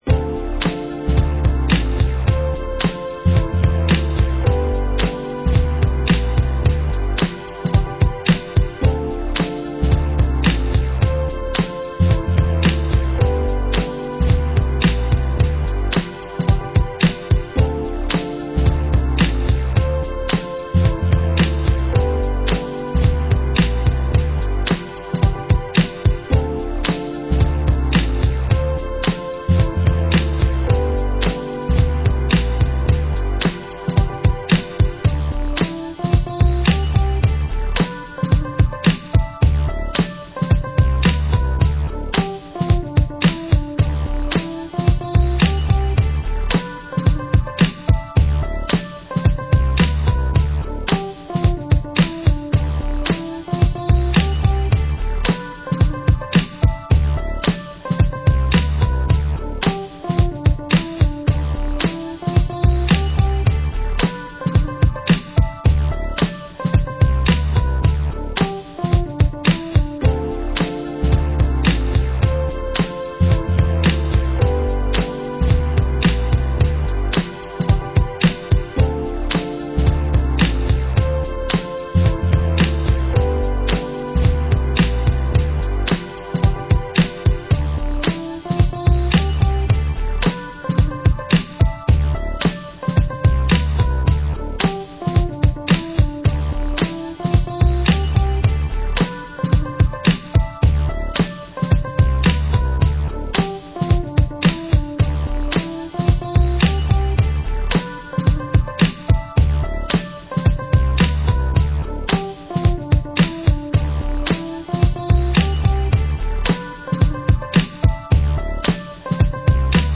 BPM109